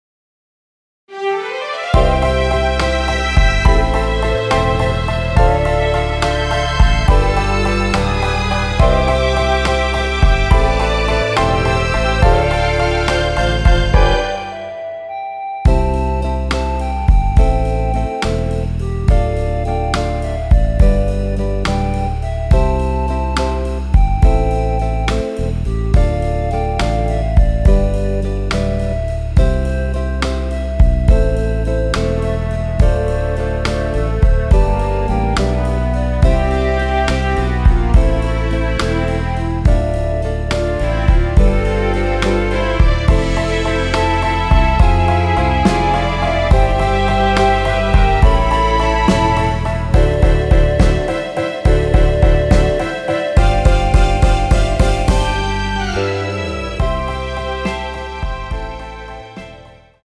원키에서(+6)올린 멜로디 포함된 MR입니다.(미리듣기 확인)
앞부분30초, 뒷부분30초씩 편집해서 올려 드리고 있습니다.